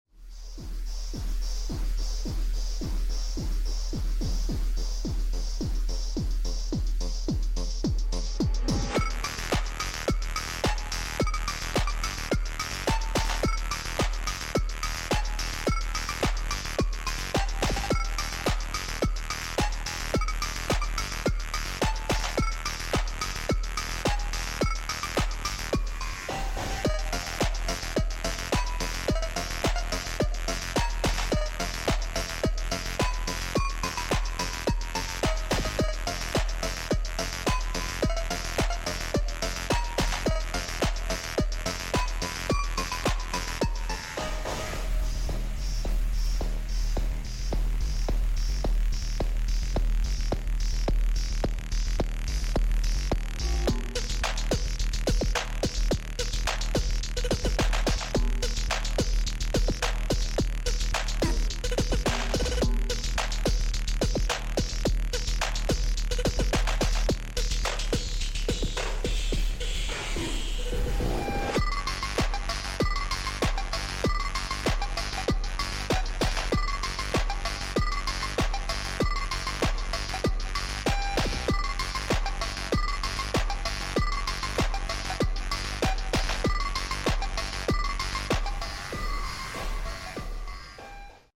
Full Sped Up version